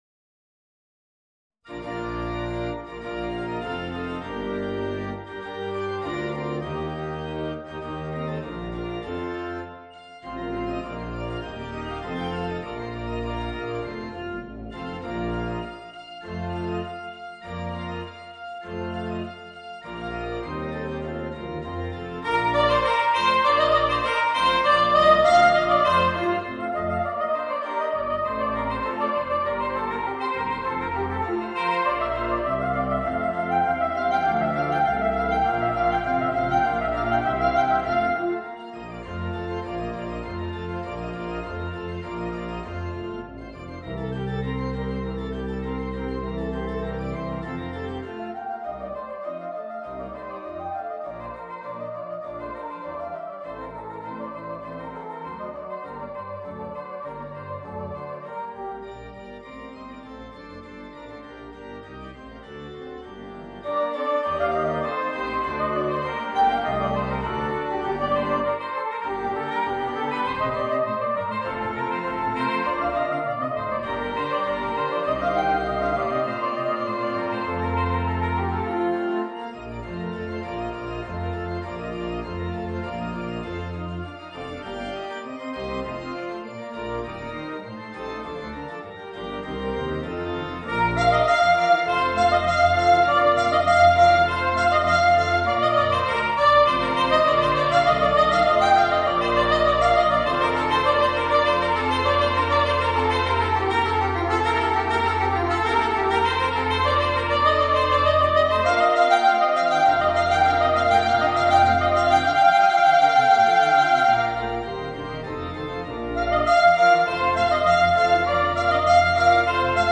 Voicing: Soprano Saxophone and Organ